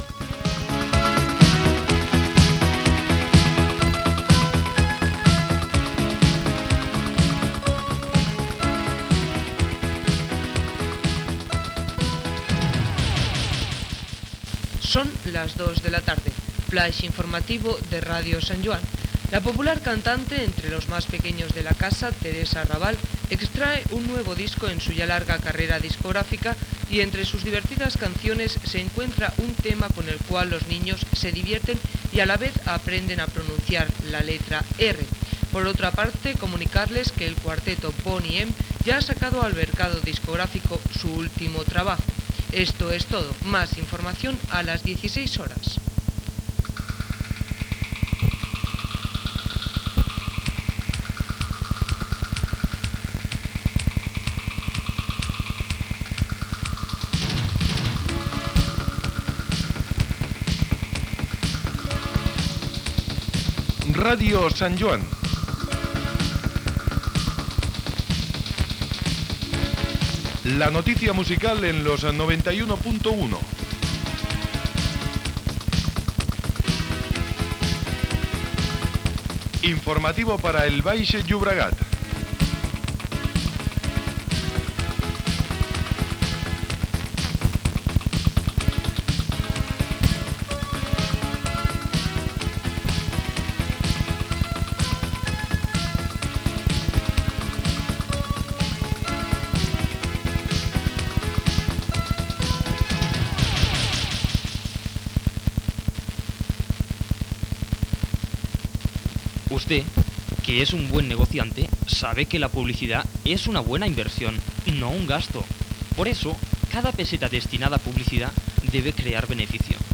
Gènere radiofònic Musical Data emissió 1985-03 Banda FM Localitat Sant Joan Despí Durada enregistrament 02:48 Idioma Castellà Any 1985